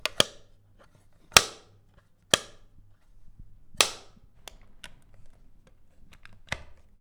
Power Plug with Plastic Lid for Shower Sound
household